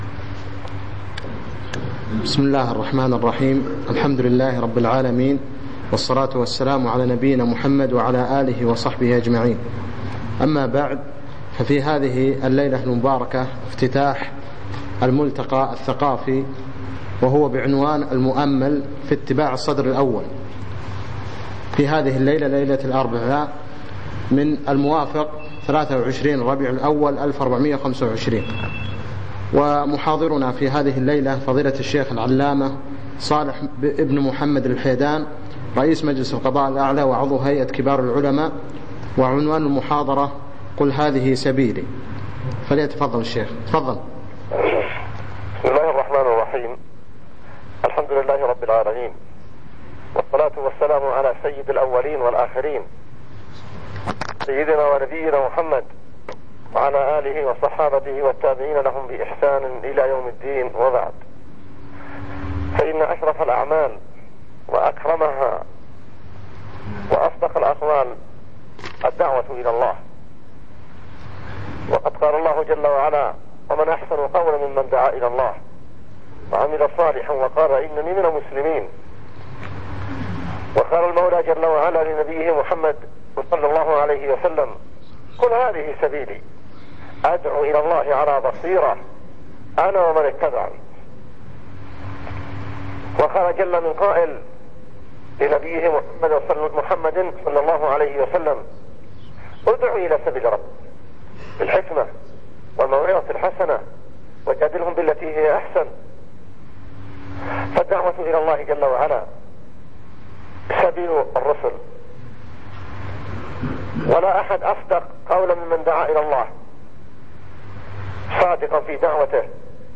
افتتاح الملتقى الثقافي المؤمل في اتباع الصدر الأول ليلة الأربعاء 23 ربيع الأول 1425 في مسجد كلندر
كلمة بعنوان ( قل هذه سبيلي ) اتصال هاتفي